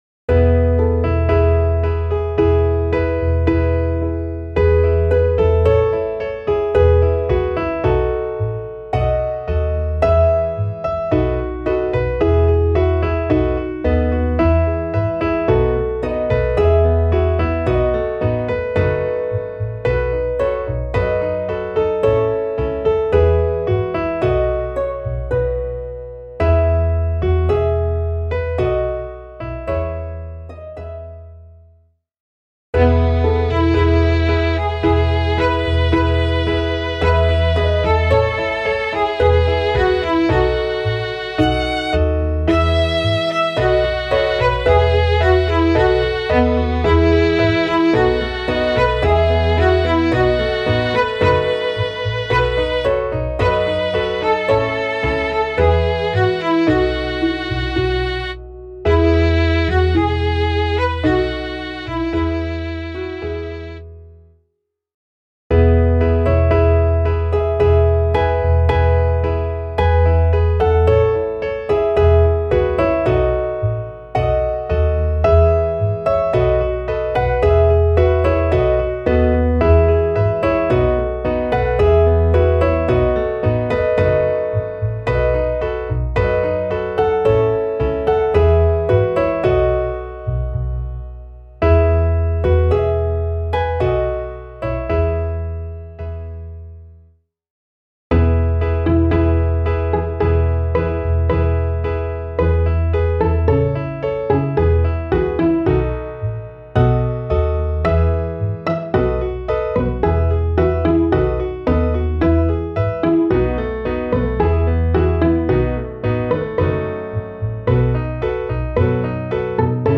Klaviersätze Wa bis We